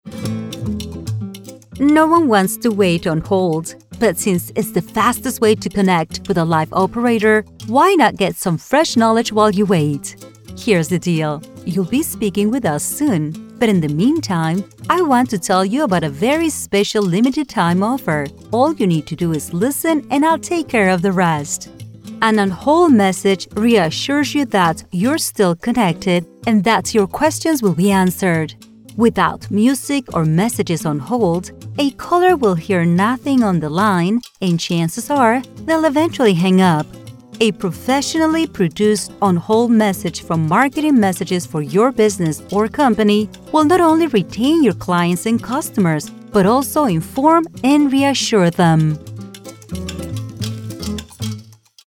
Voice Diversity Sample Audio
Spanish-accented English Voices: